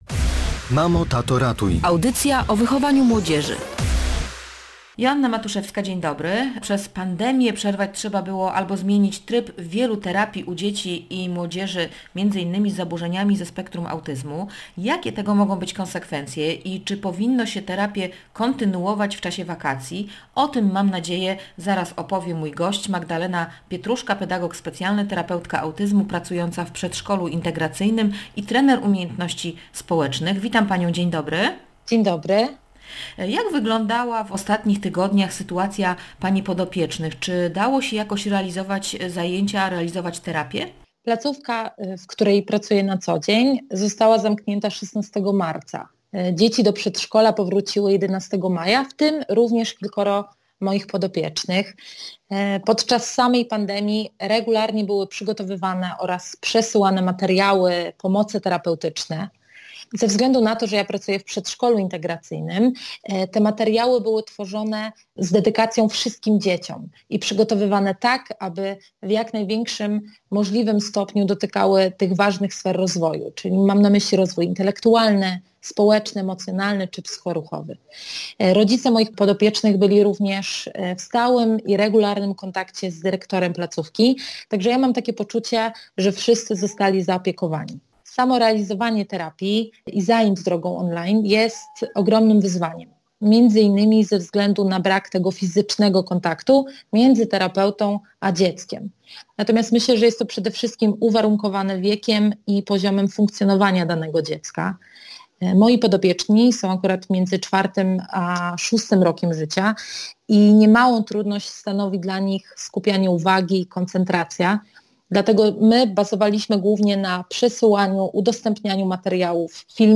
mówiła w Radiu Gdańsk
pedagog specjalny i terapeutka autyzmu.